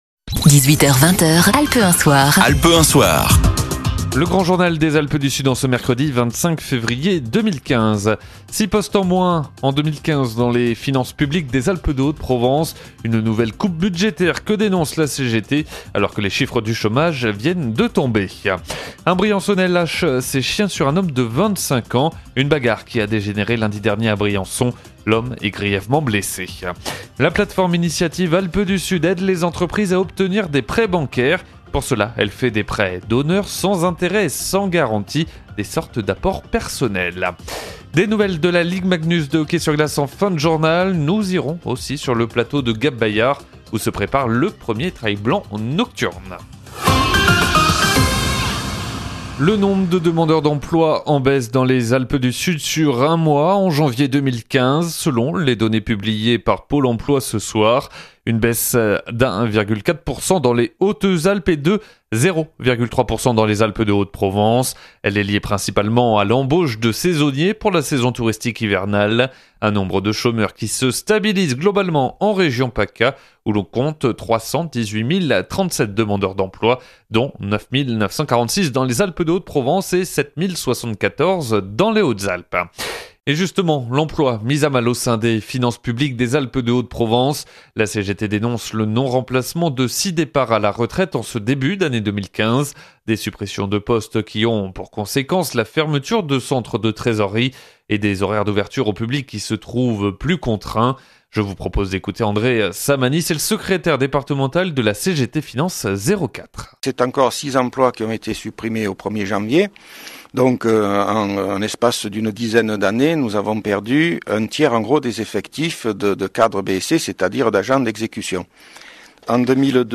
INTERVENTION CGT04 sur la RADIO "ALPES 1"
INTERVIEW :